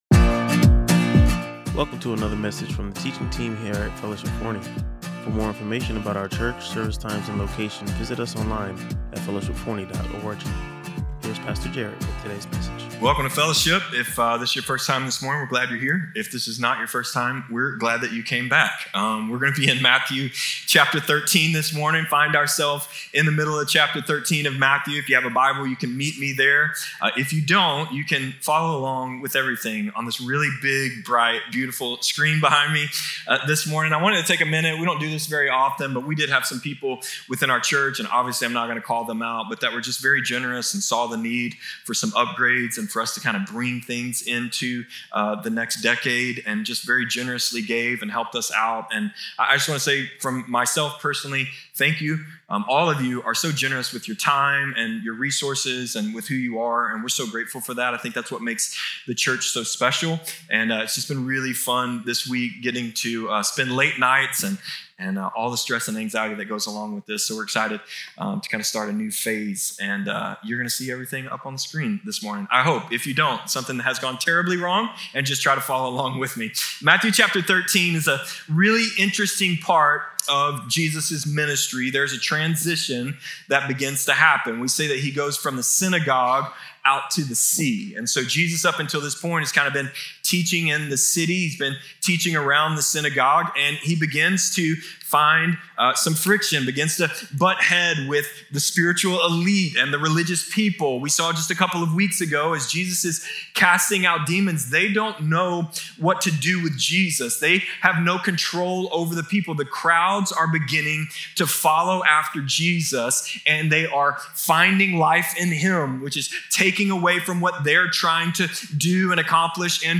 Listen to or watch the full sermon and to understand how these ancient parables apply to our modern lives.